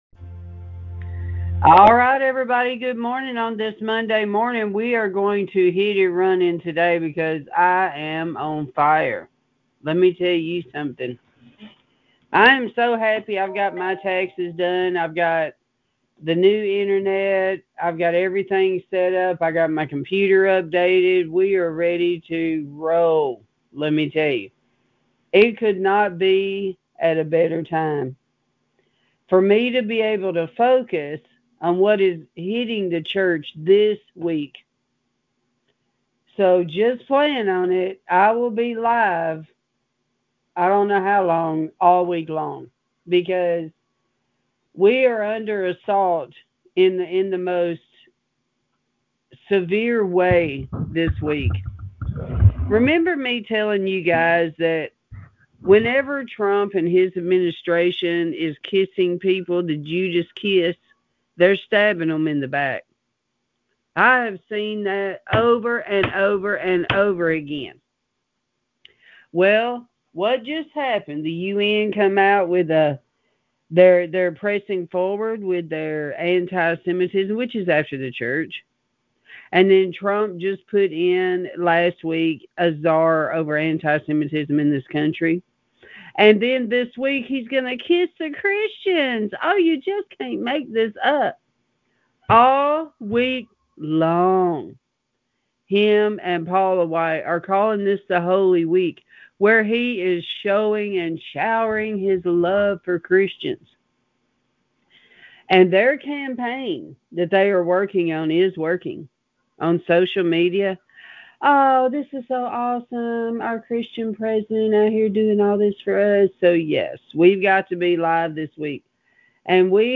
Sermons | Garden of Eden Ministries
This week I will be LIVE everyday because the greatest deception is taking place at the White House.